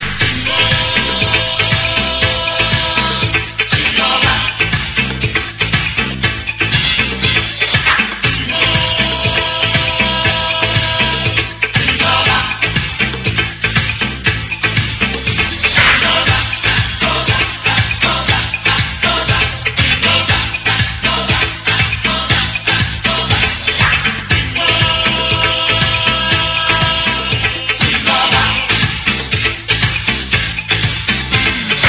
disco classic